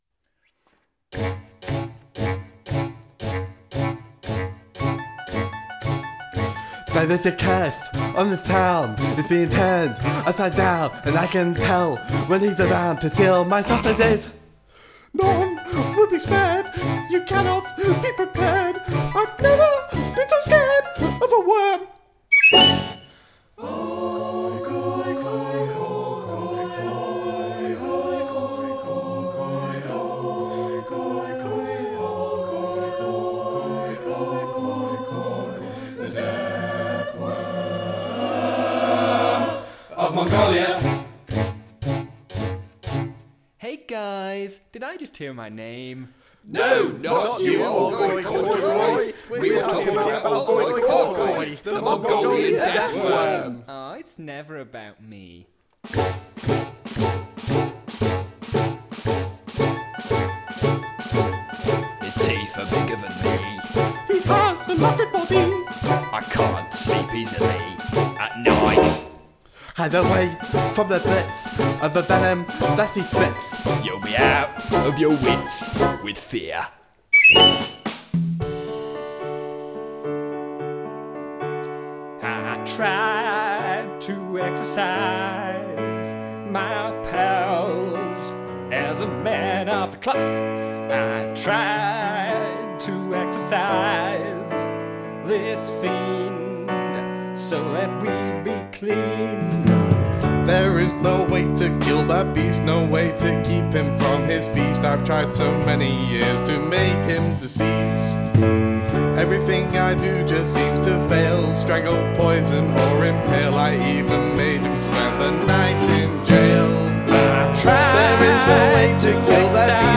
Barbershop Quartet
Bass guitar
Drums
Keyboard
Tuba
Ukulele